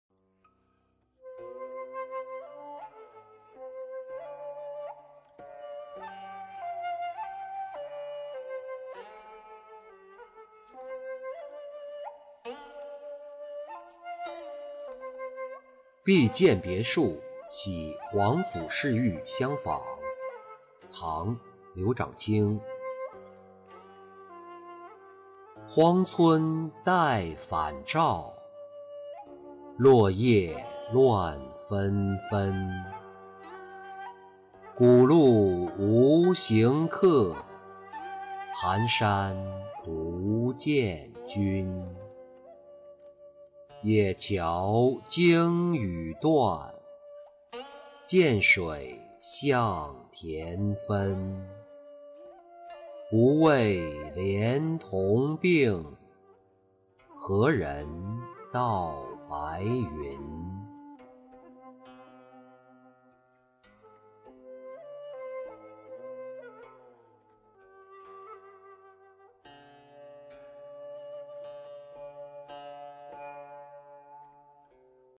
碧涧别墅喜皇甫侍御相访-音频朗读